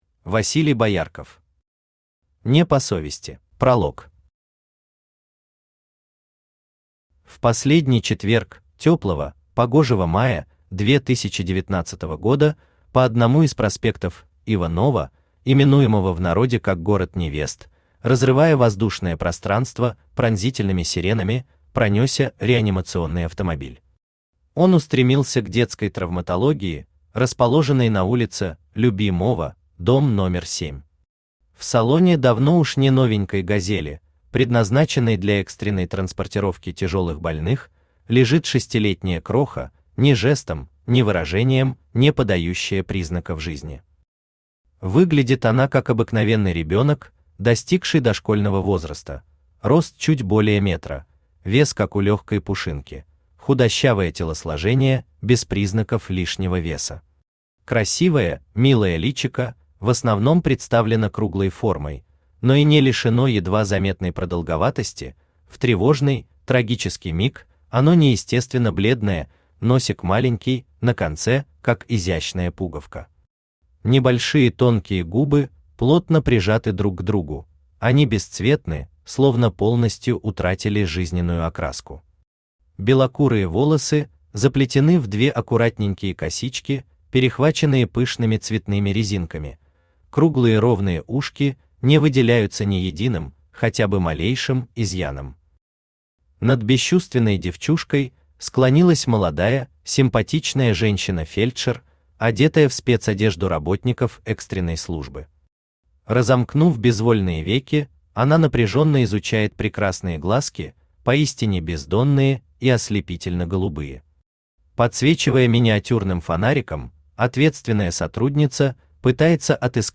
Аудиокнига Не по совести | Библиотека аудиокниг
Aудиокнига Не по совести Автор Василий Боярков Читает аудиокнигу Авточтец ЛитРес.